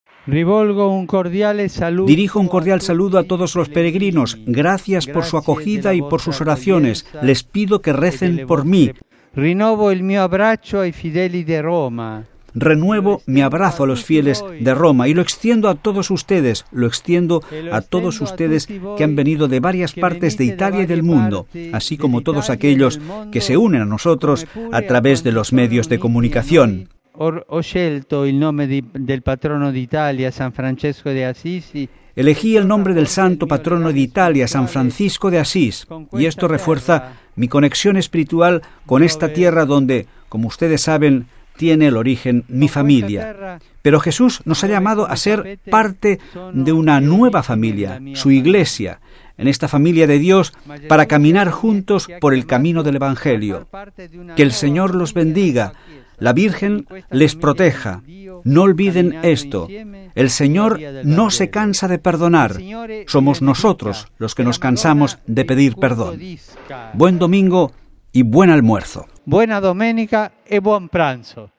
Y después de la oración mariana del Ángelus el Papa Francisco ha dirigido un cordial saludo a todos los peregrinos reunidos en la plaza de san Pedro: unas 150 mil personas según cálculos de la Oficina de prensa de la Santa Sede.